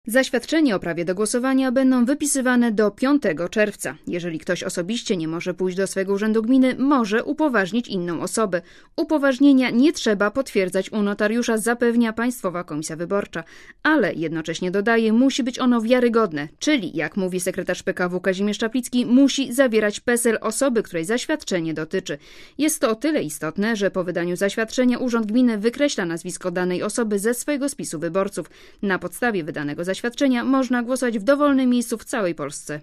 Sprawą zajęła się reporterka Radia Zet (260Kb)